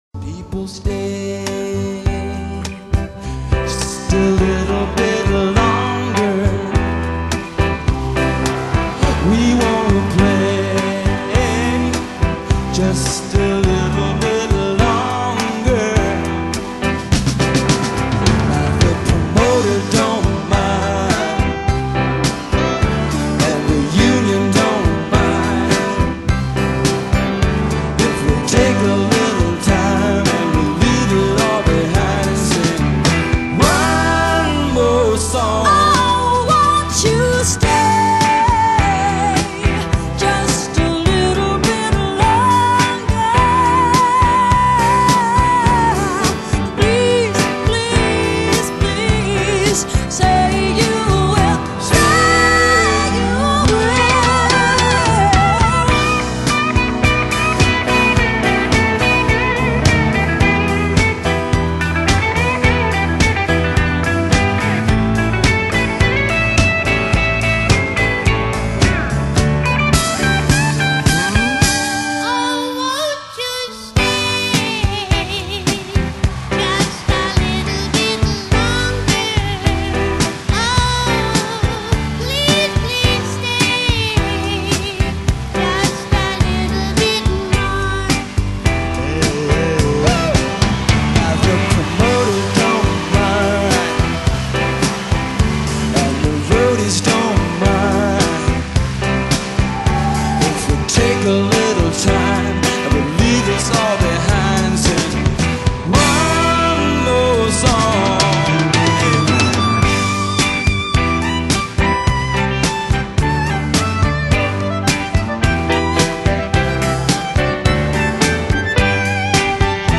Genre: Pop / Rock